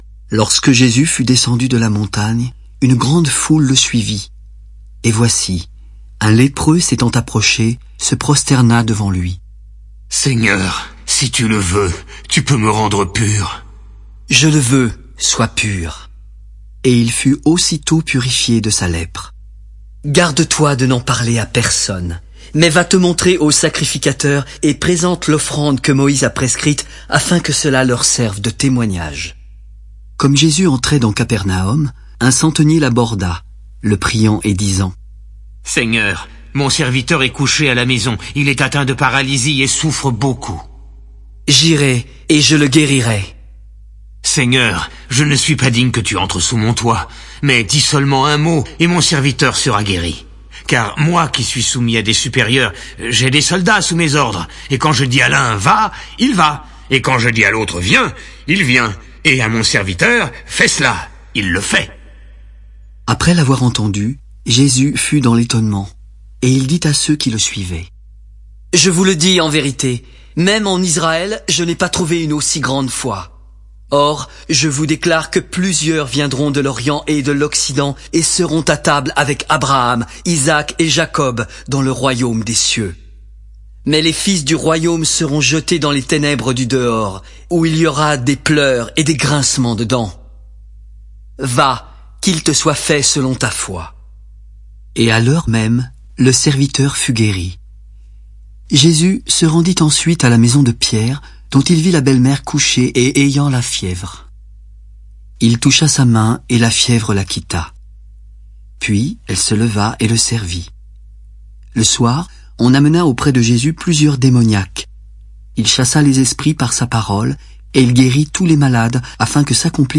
[Lecture théâtrale] Évangile selon Matthieu — Partie 1
RETROUVEZ l'intégralité des quatre Évangiles retraçant la vie terrestre du Seigneur Jésus-Christ, dans une lecture théâtrale réconfortante.